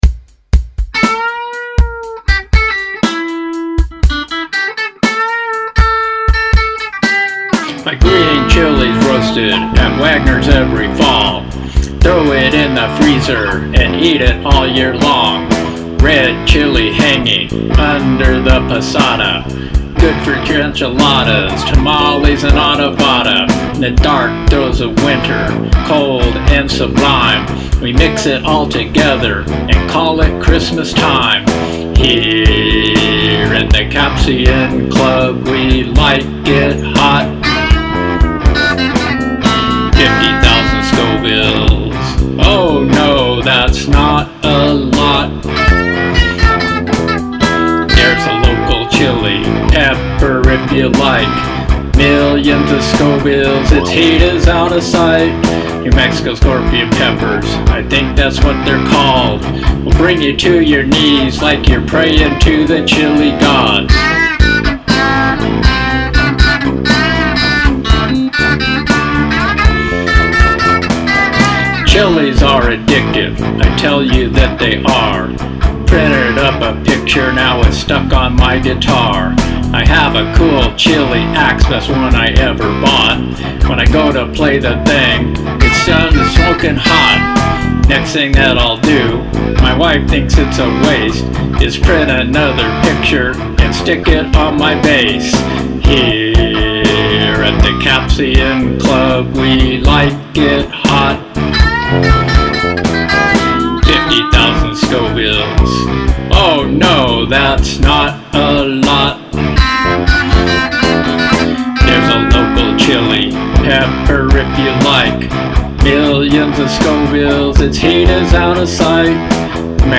vocals, rhythm and lead guitars, digital percussion
bass guitar